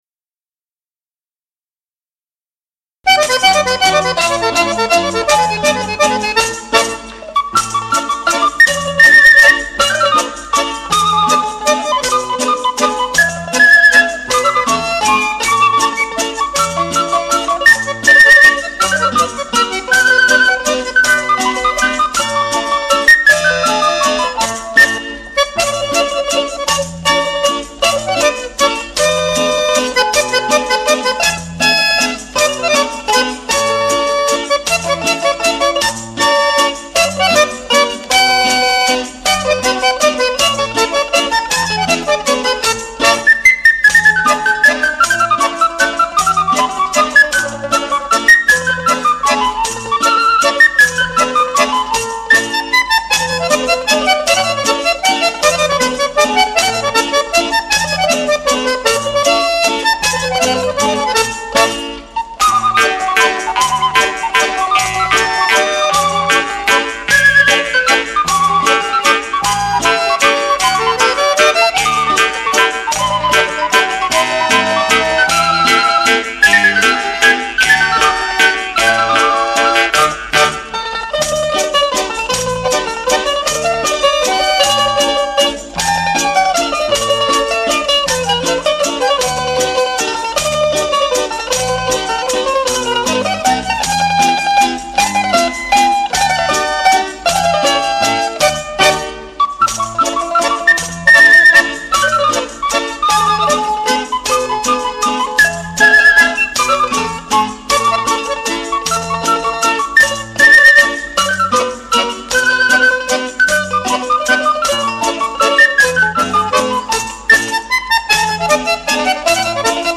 COMPLESSO CARATTERISTICO SICILIANO
ZUFOLO
FISARMONICA